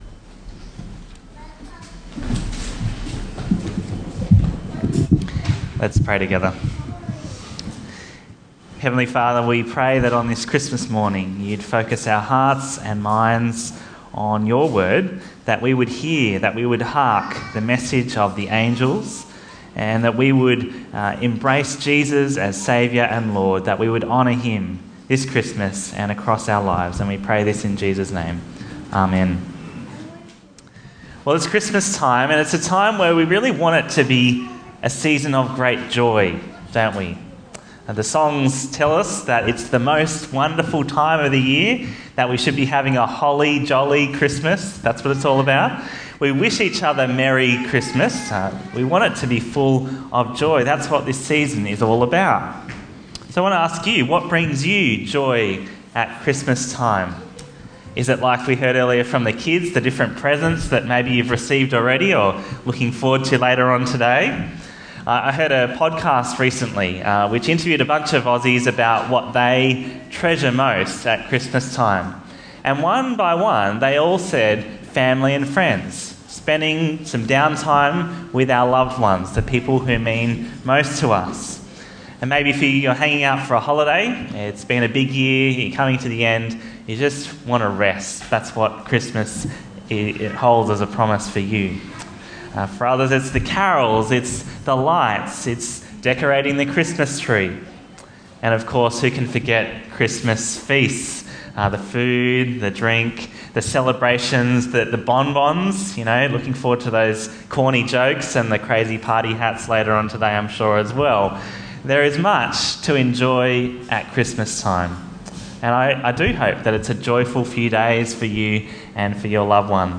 Bible Talks
Christmas-Service-2019.m4a